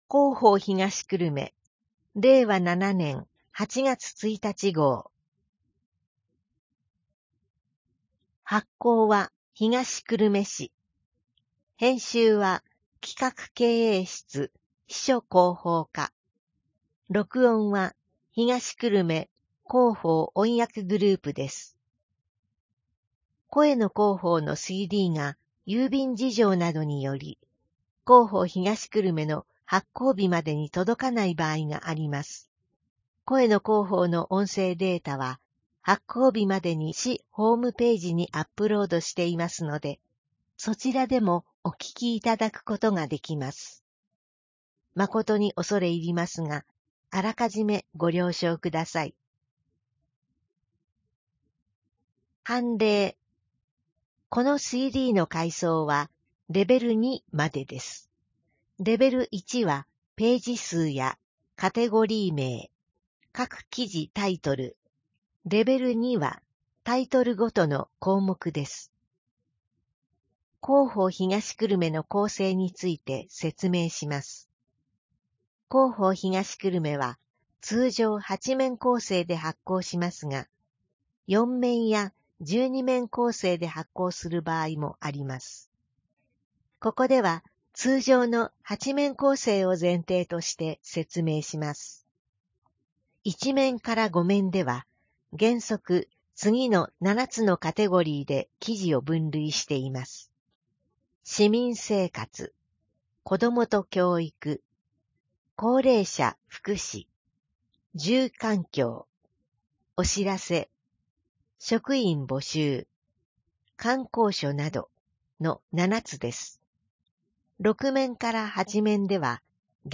声の広報（令和7年8月1日号）